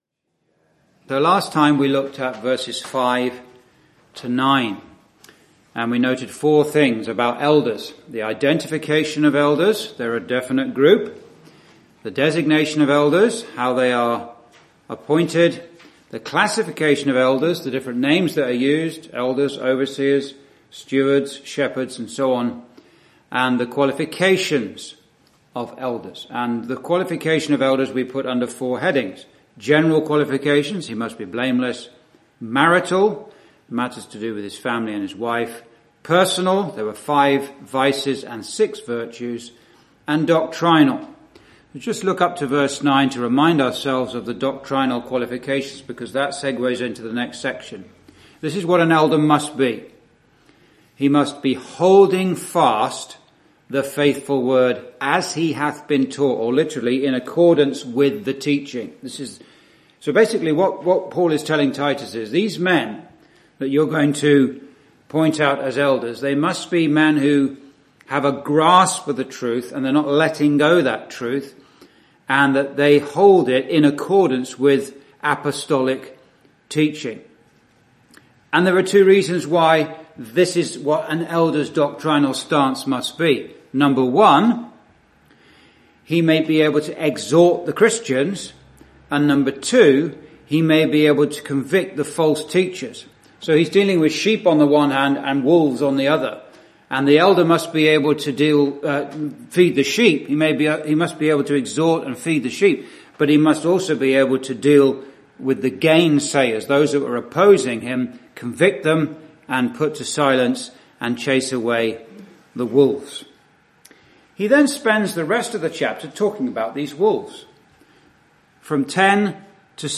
(Message preached in Chalfont St Peter Gospel Hall, 2022)
Verse by Verse Exposition